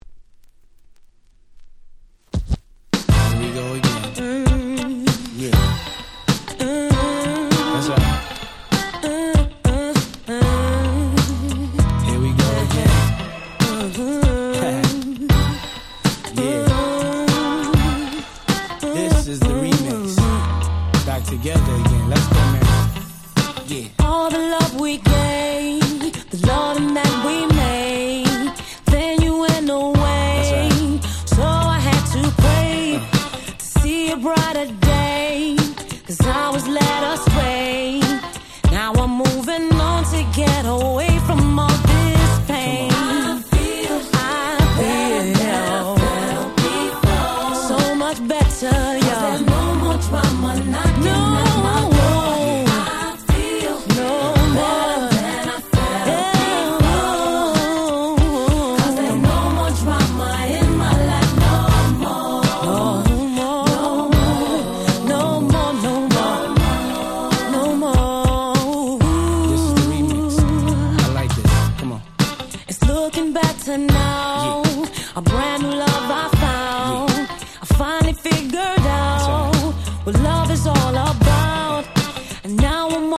02' Smash Hit R&B / Hip Hop Soul !!